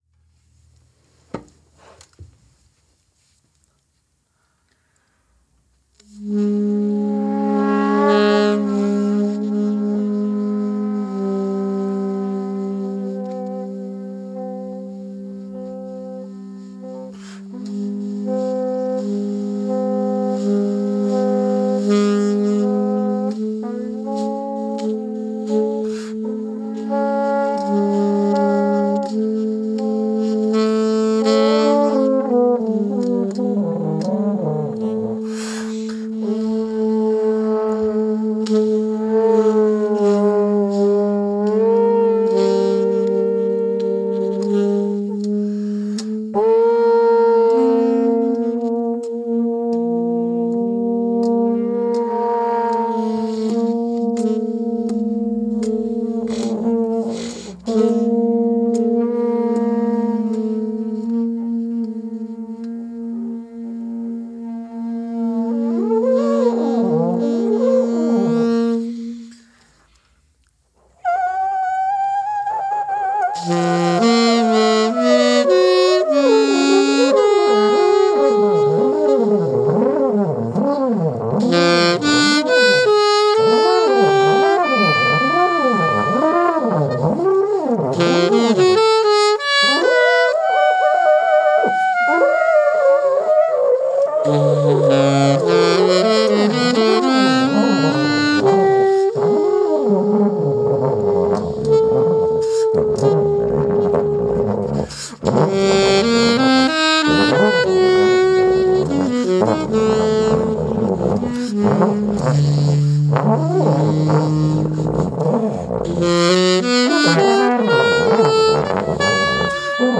In dieser Folge wird wieder einmal frei improvisiert! Geräuschhaft und auf Bewegungsabläufe fokussiert. Mit anschliessender Reflexion versteht sich.